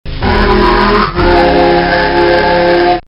Sega Scary Sound Effect Free Download